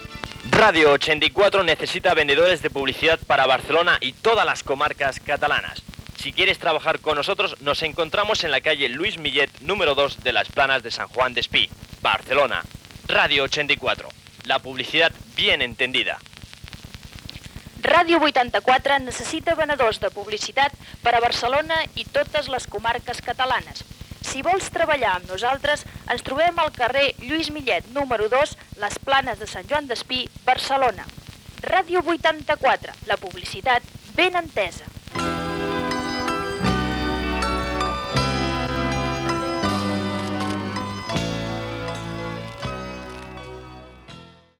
Gènere radiofònic Publicitat